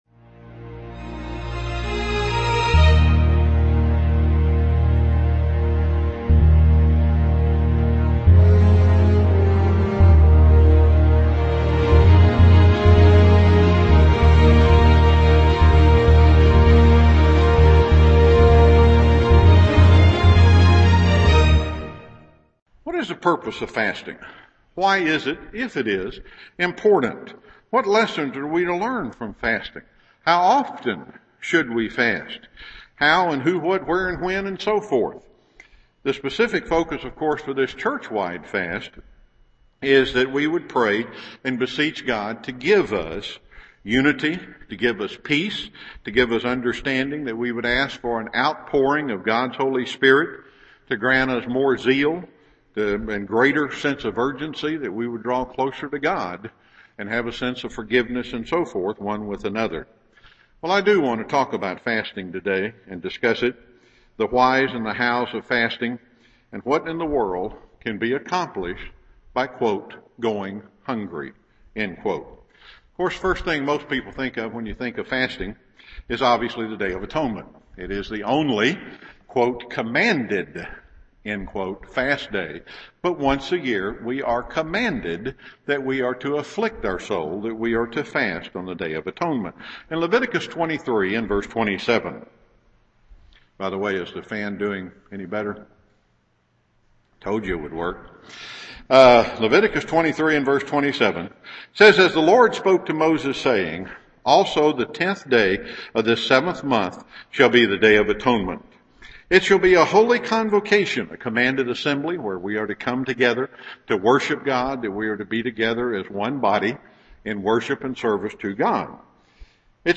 Given in Chattanooga, TN
Isaiah 58:1-12 UCG Sermon